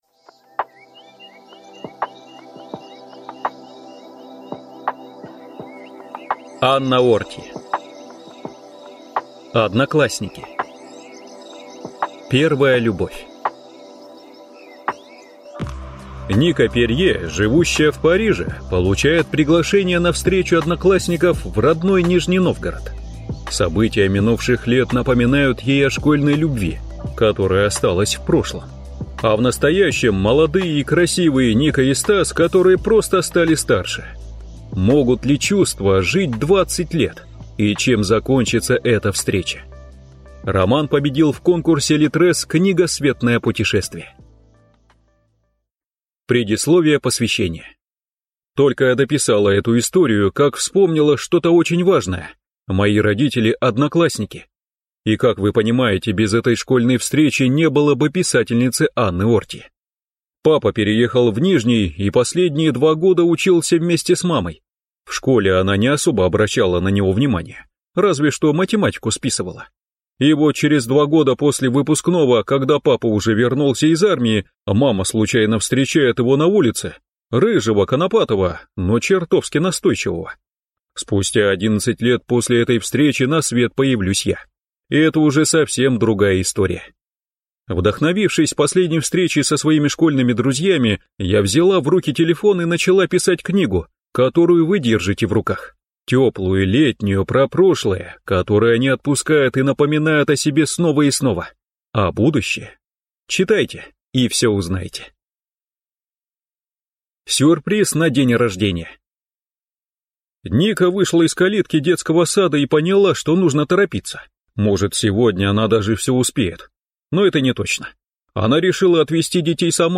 Аудиокнига ОдноклассНики: первая любовь | Библиотека аудиокниг